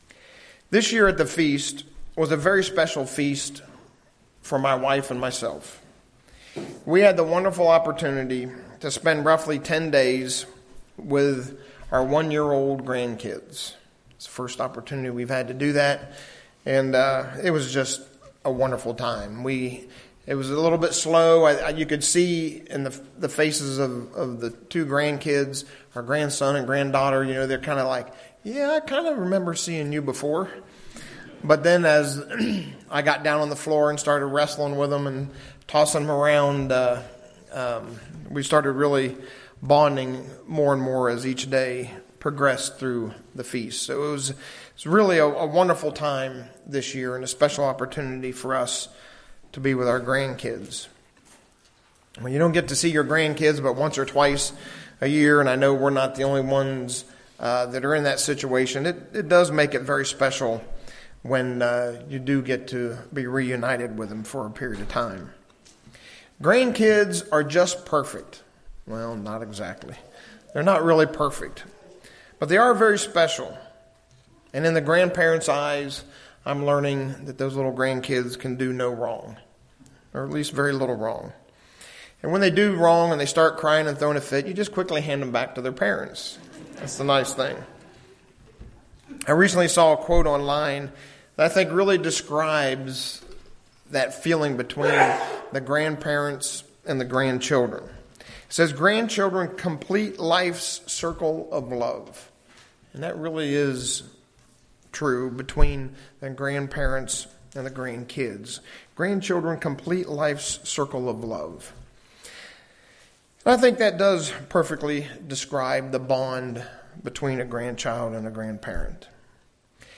This sermon explores Matthew 18:3-4 and what that lesson is.
Given in Ft. Wayne, IN